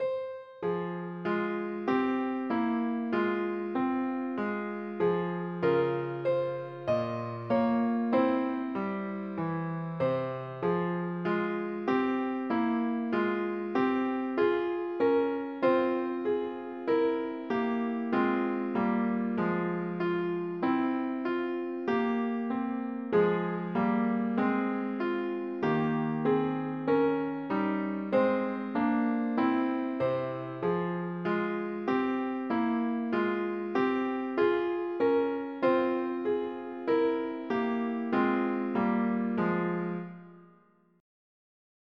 Children's Songs